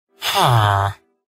Category: Video Game Ringtones